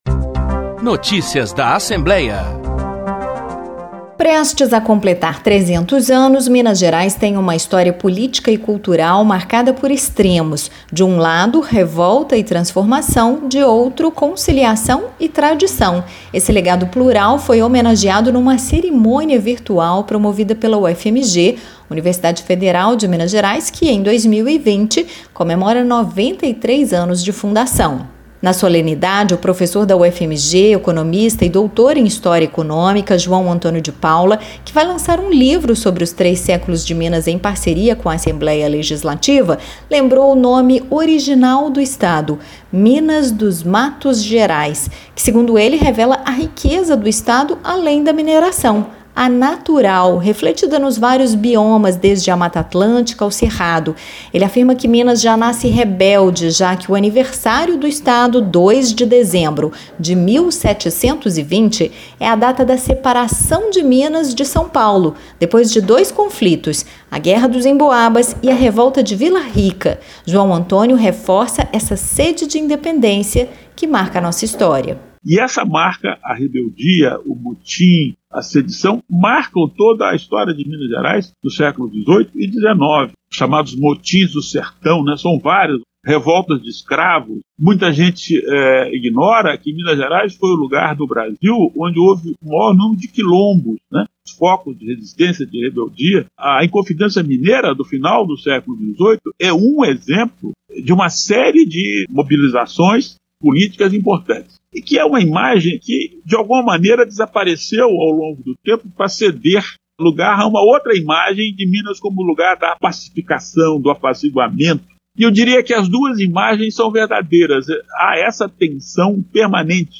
Deputado Agostinho Patrus participou, nesta terça (8), de conferência sobre a universidade e os 300 anos de Minas.
Em seu discurso, o parlamentar destacou a importância da universidade para Minas e para o País, assim como as parcerias firmadas com a Assembleia em ações e pesquisas relacionadas ao enfrentamento do coronavírus.